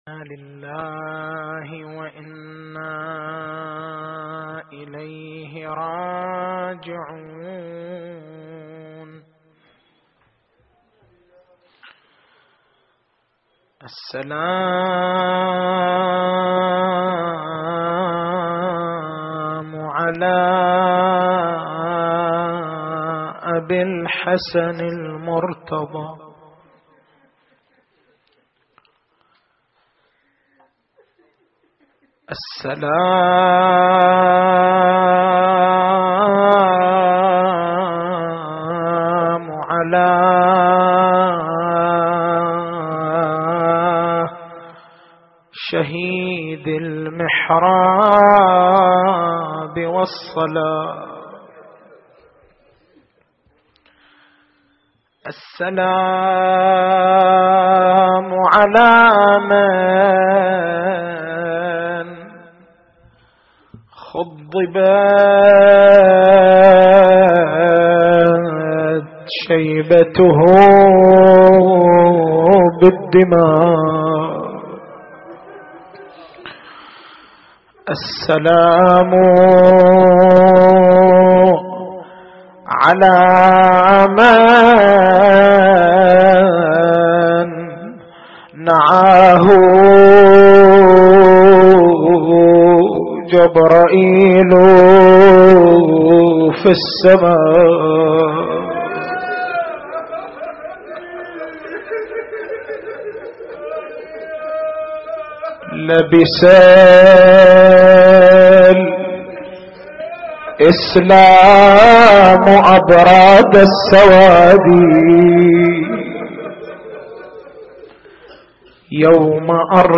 تاريخ المحاضرة